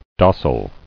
[dos·sal]